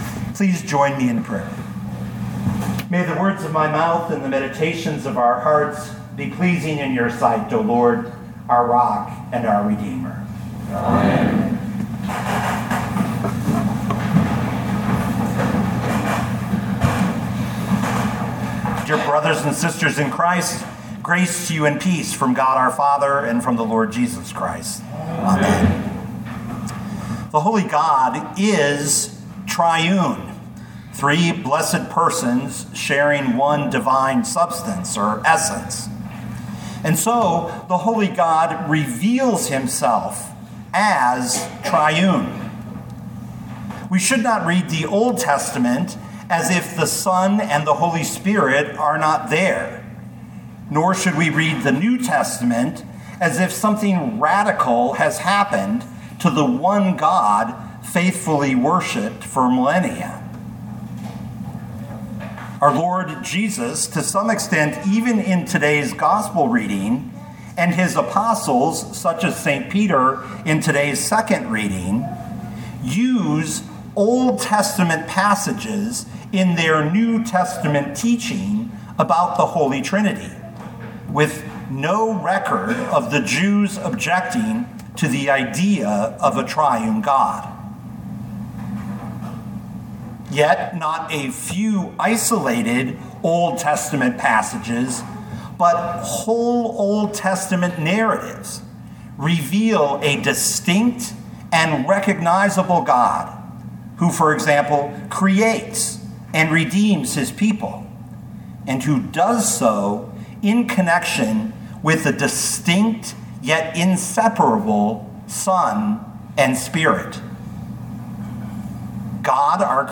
2024 Isaiah 6:1-8 Listen to the sermon with the player below, or, download the audio.